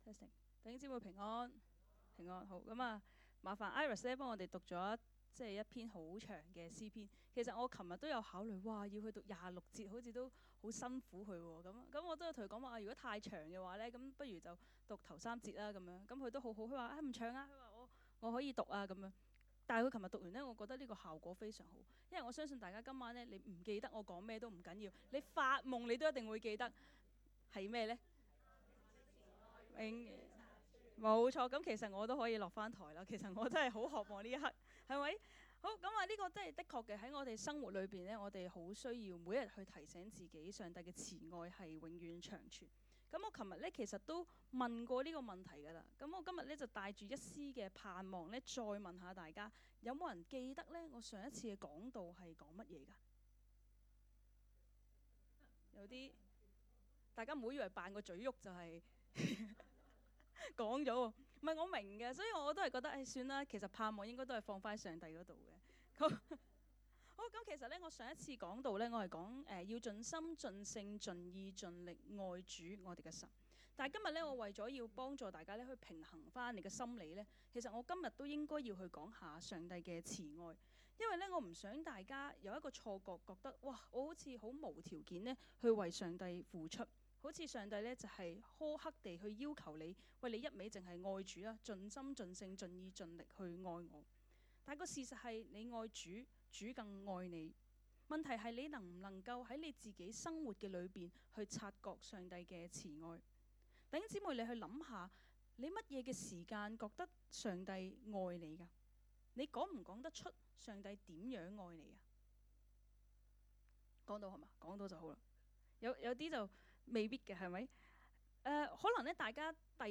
講道 :祂的慈愛永遠長存